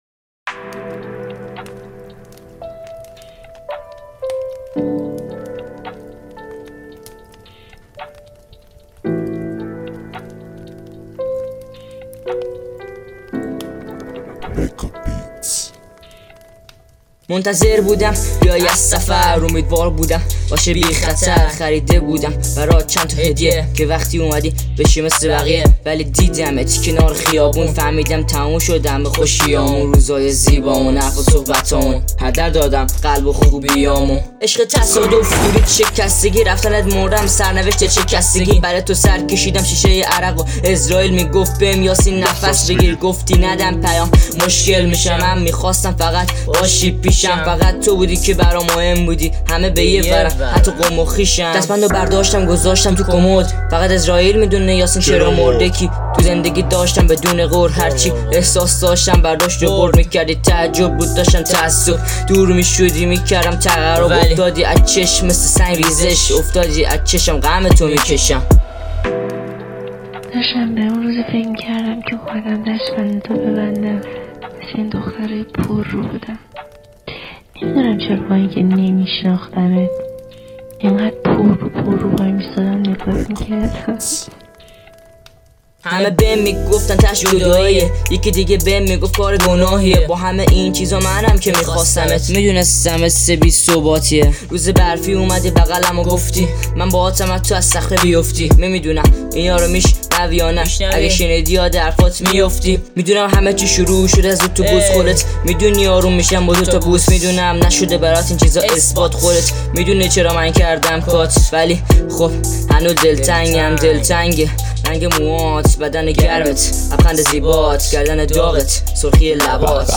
Persian RAP Rapfarsi R&B Trap
رپ رپفارسی